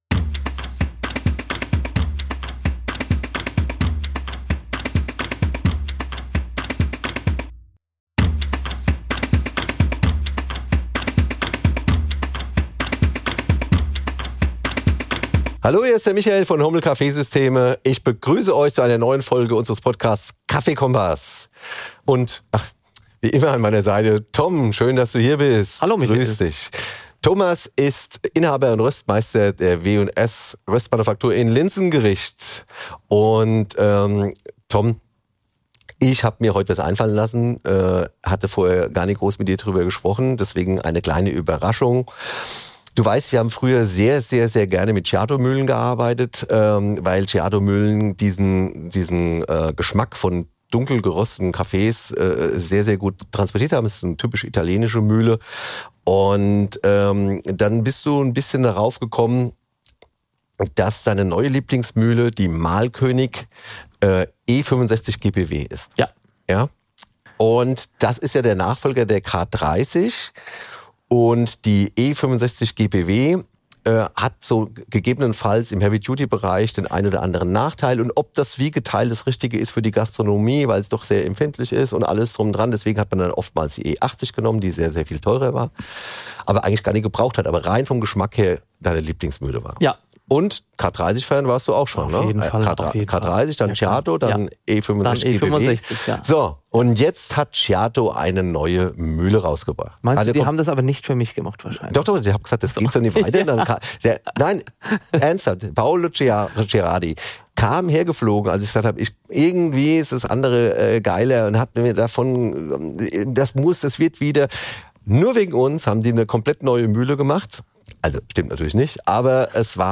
Beides sprüht dem Hörer der KaffeeKOMPASS Podcast-Reihe ab der ersten Sekunde entgegen, wenn zwei ausgewiesene Fachleute rund um das schwarze Gold fachsimpeln.